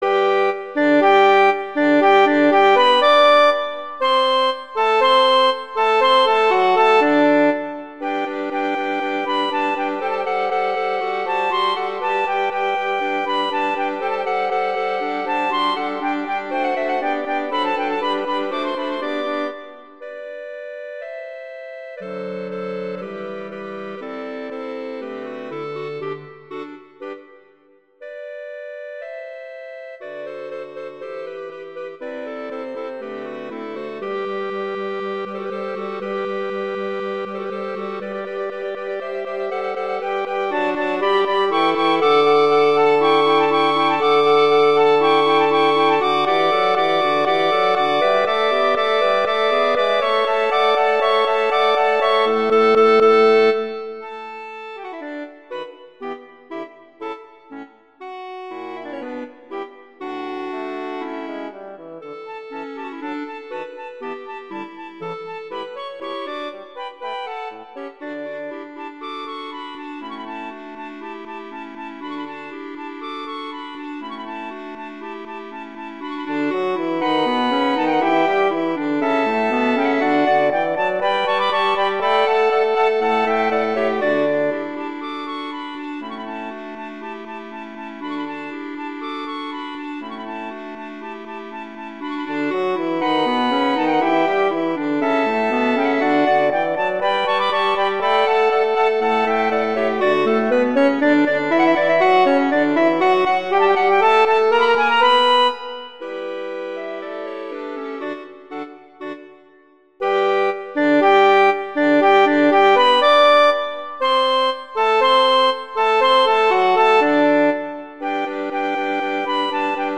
classical
G major, C major
♩=80-176 BPM